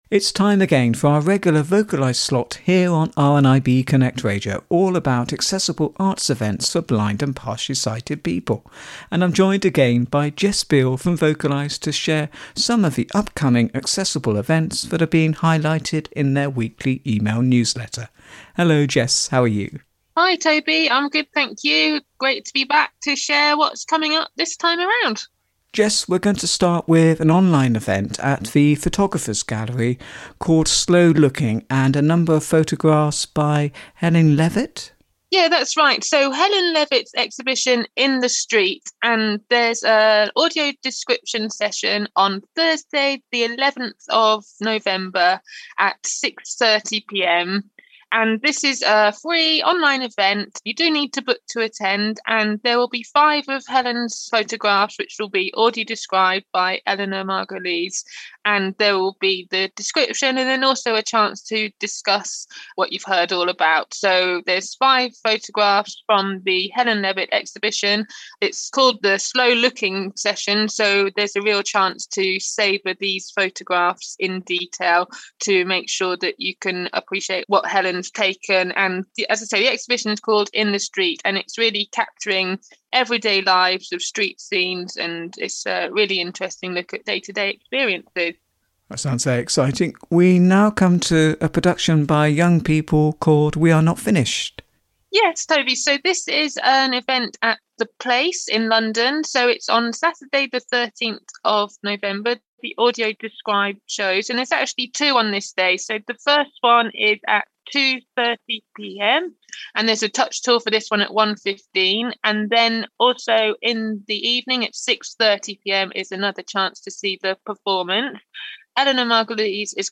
RNIB Conversations